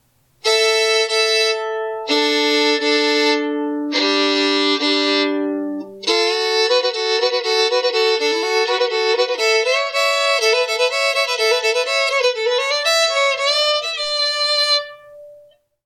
Vintage 7/8 Scale German Violin/Fiddle $725
I would classify this one as loud to very loud in volume. Great projection and will get you heard in any acoustic jam setting!